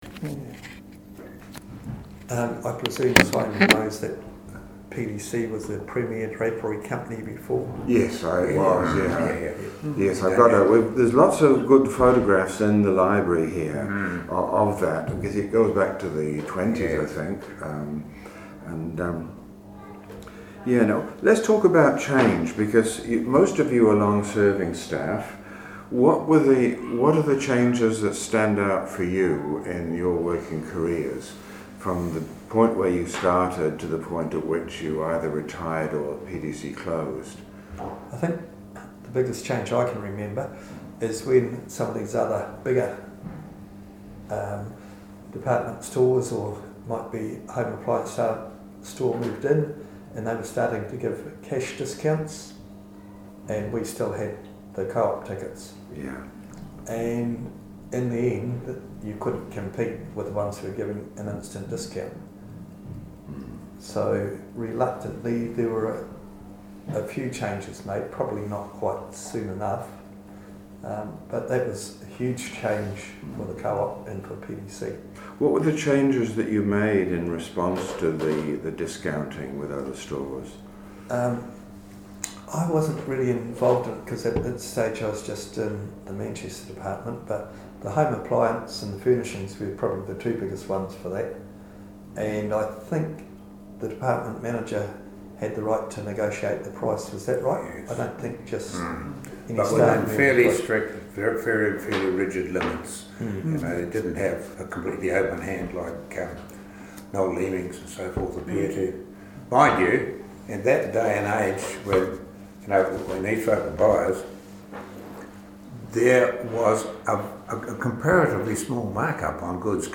Oral Interview - Past Employees of the PDC Department Store, part 2 - Manawatū Heritage
Location: Wharite Room, P.N. City Library. Notes: Recording slightly compromised by acoustics of the room and varying distance of interviewees from recorder.